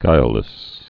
(gīllĭs)